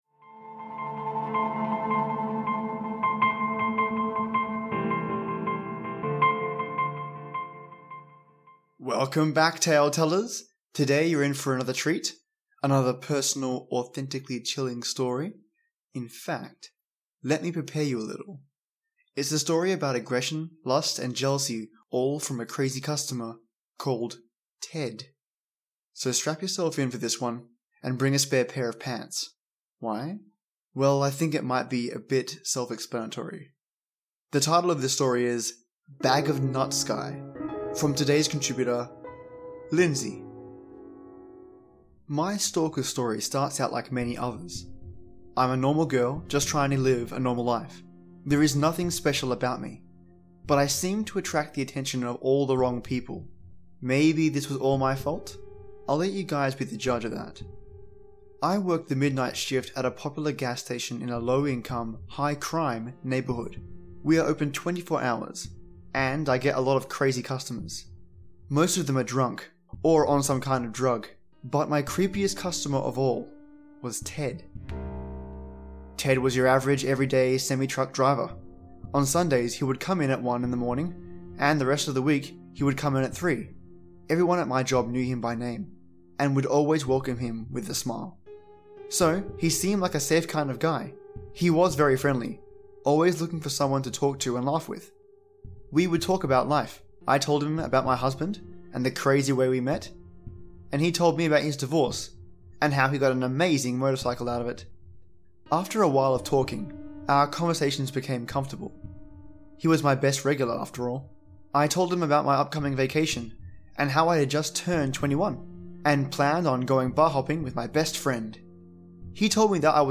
A Personal true story, straight from a contributor!
Sci-fi Background Music